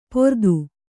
♪ pordu